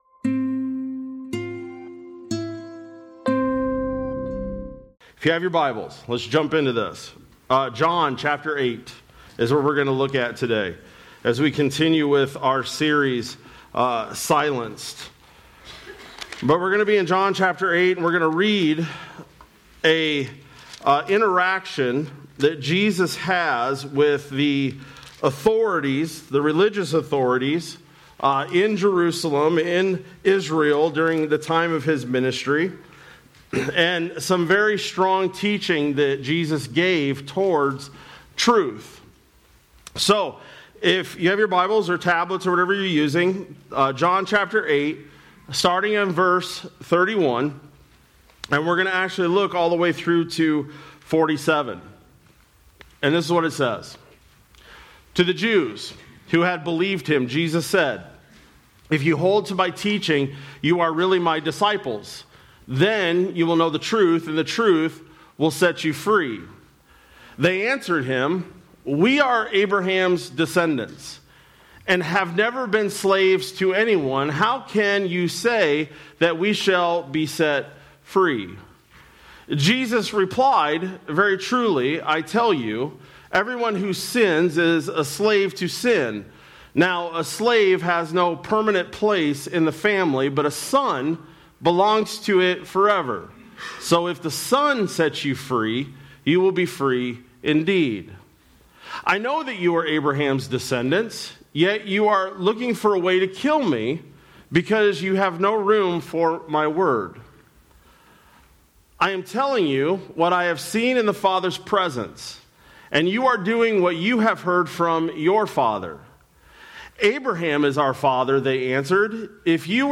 Sermon-Nov-16-25-Audio.mp3